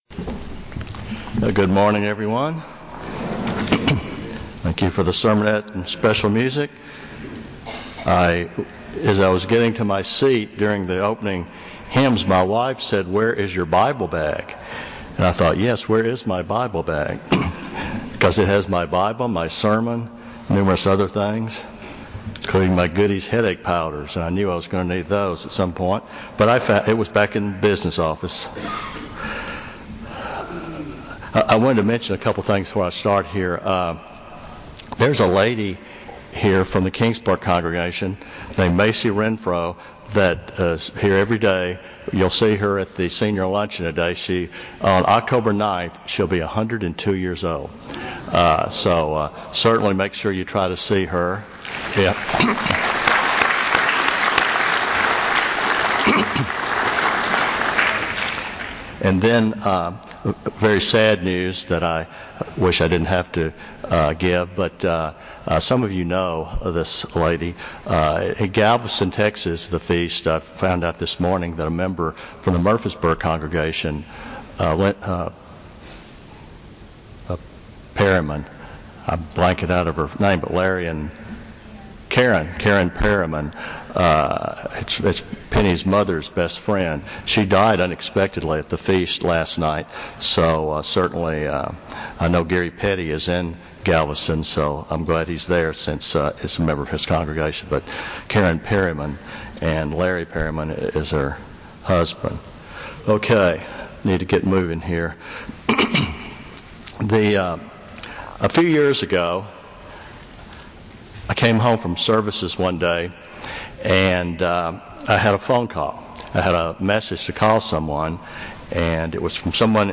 This sermon was given at the Jekyll Island, Georgia 2018 Feast site.